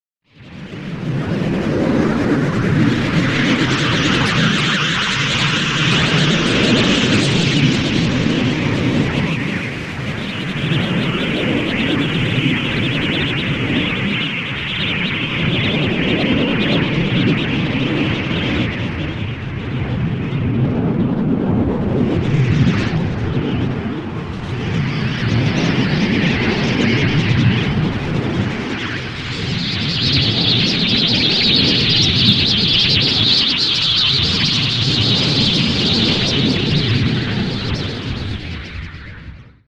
恐怖を感じさせる効果音です。